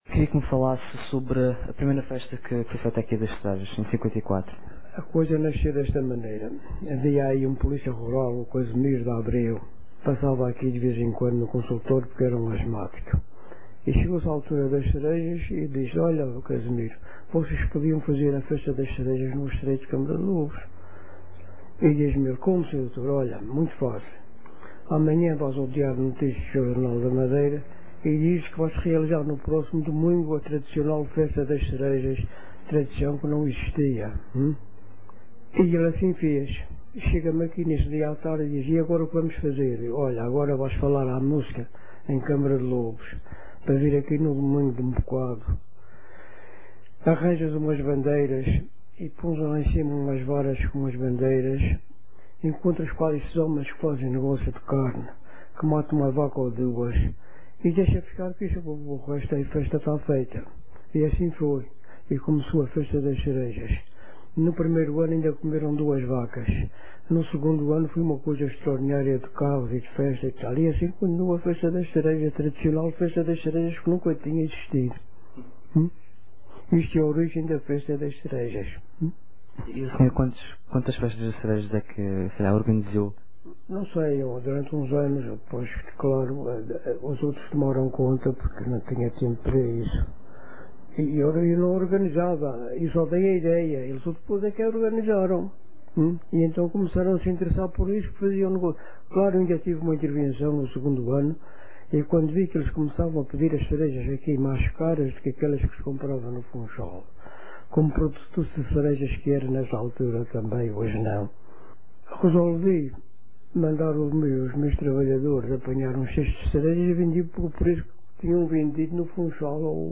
Porque o ficheiro de som anexo a esta página de redireccionamento é de grandes dimensões, você poderá ter de esperar alguns minutos até que comece a ouvir o discurso.